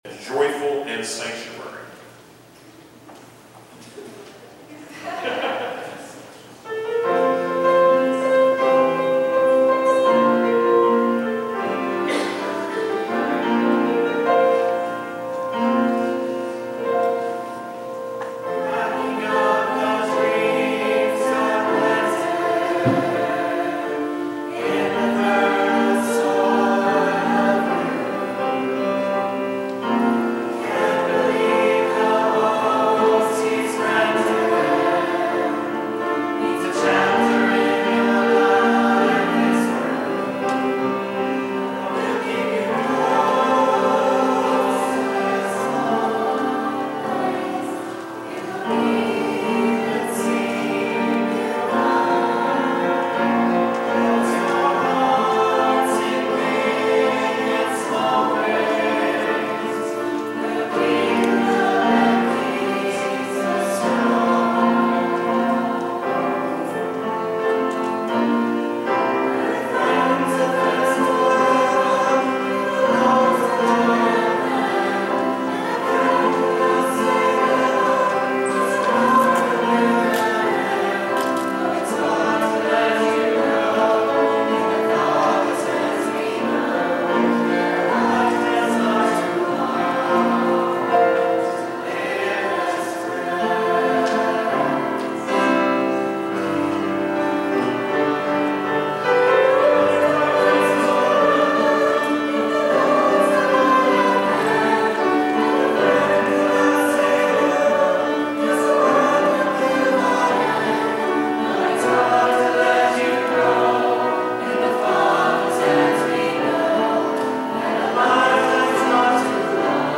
Following the Worship Service and the Anniversary Dinner, the day’s events Sunday concluded with a Music Celebration service.
Various vocals and instrumentals, performed by people from the youngest to the more mature were featured.
The 1990s/200 Reunion Group did a Medley of “Friend” “Joyful, Joyful and “Sanctuary”